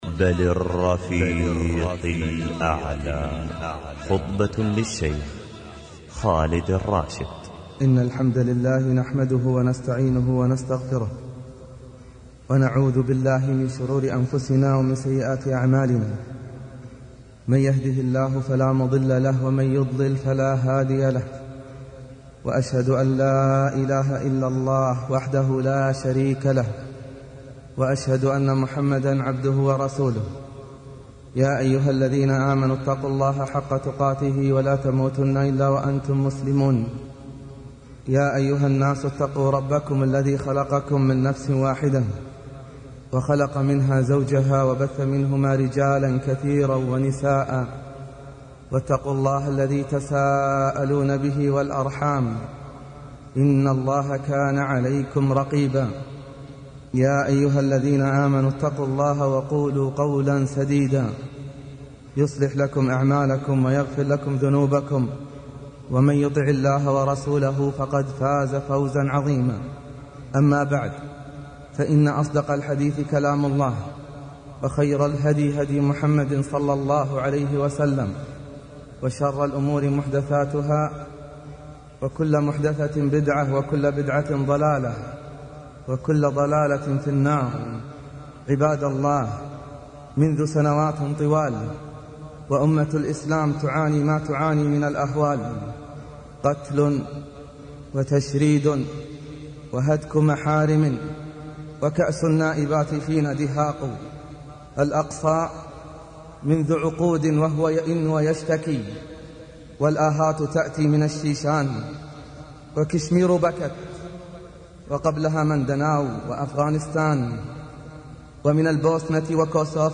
المحاضرات الصوتية